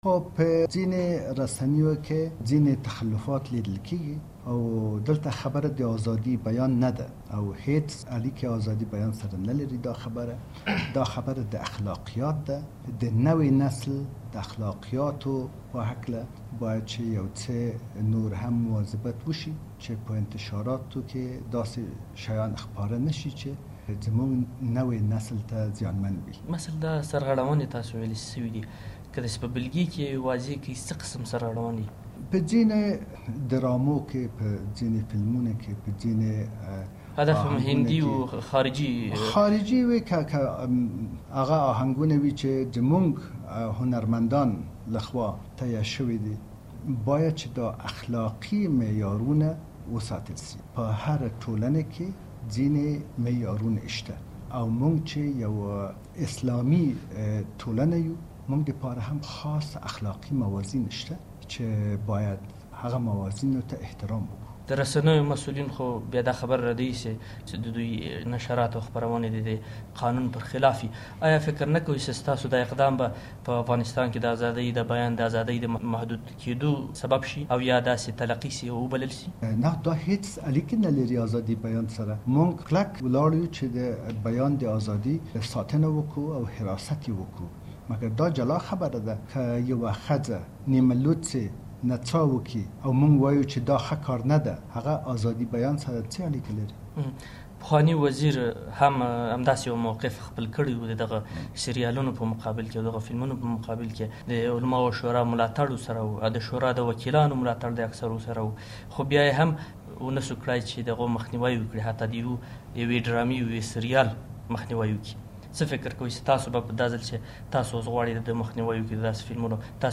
له مخدوم رهین سره مرکه